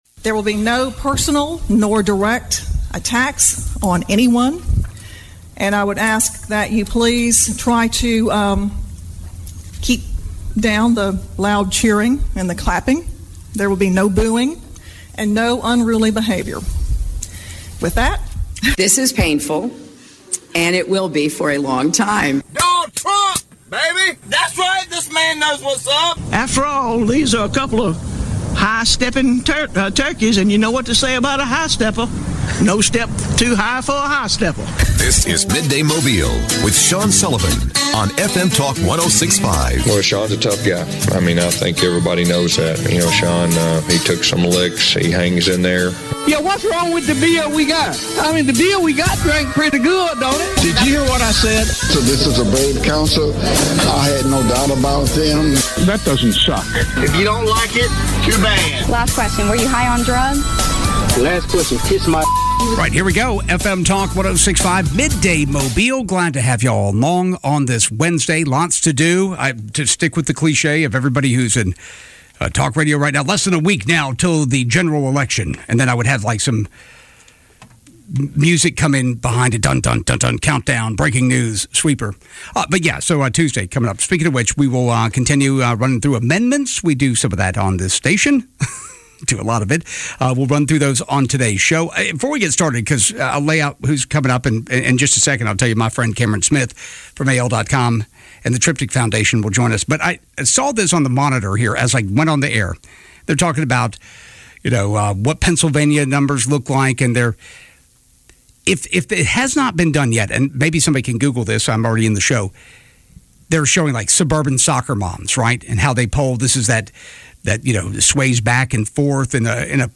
callers discuss annexation